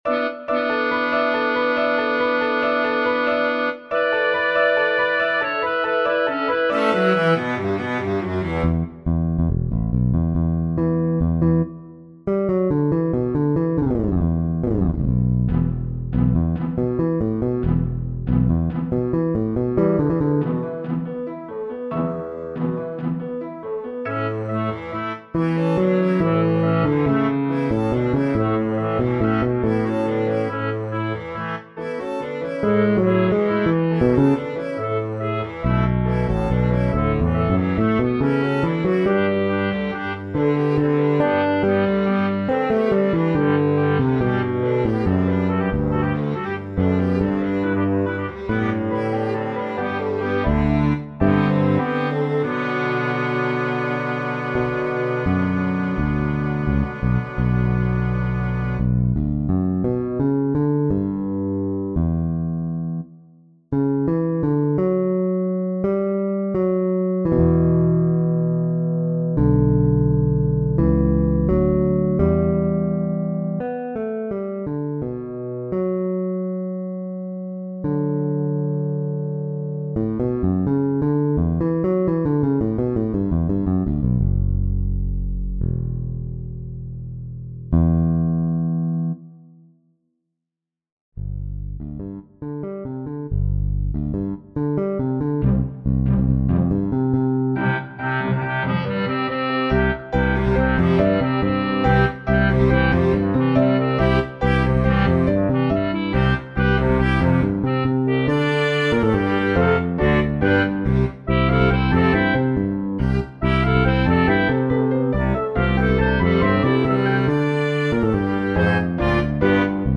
Electric Bass With 7-Piece Ensemble MS